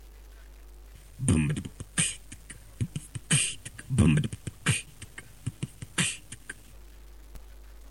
1)Bm db bb kch tk b pf b kch tk
2)BwB b kch tt ish t pf pf ish ttt